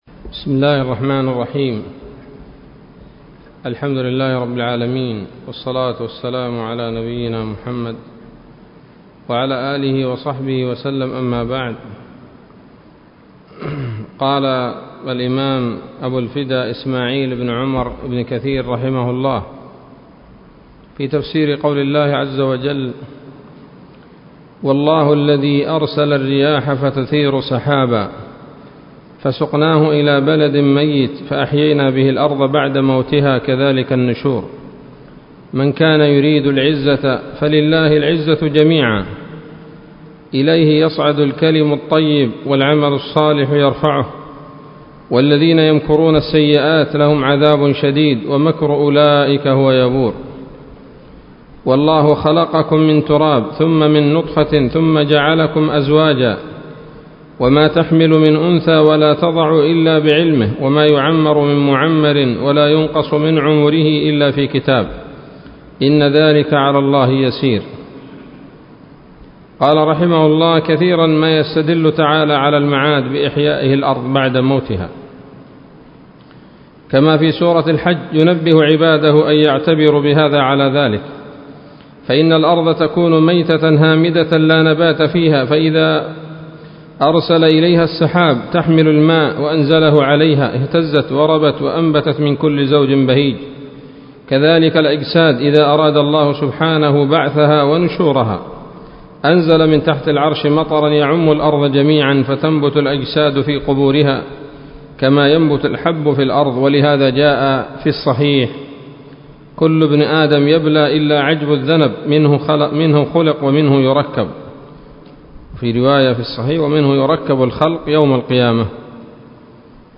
الدرس الثالث من سورة فاطر من تفسير ابن كثير رحمه الله تعالى